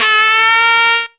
RISING WAIL.wav